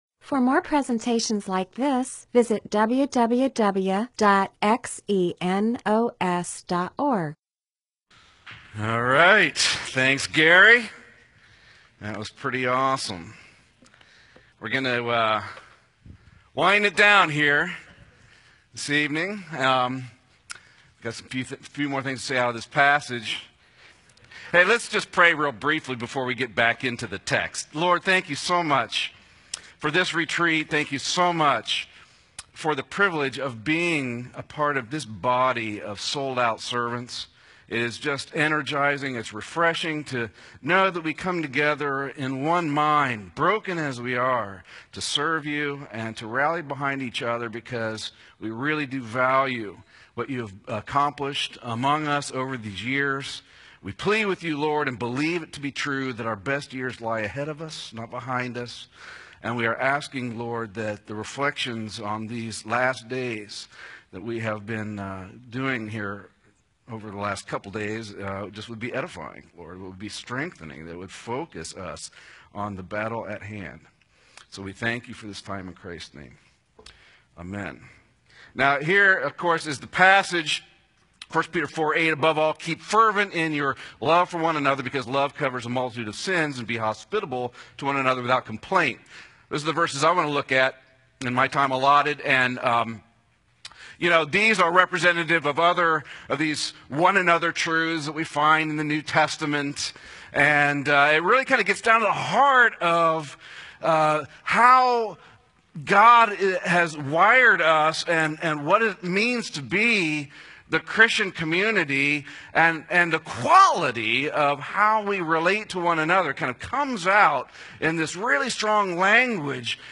MP4/M4A audio recording of a Bible teaching/sermon/presentation about 1 Peter 4:7-11.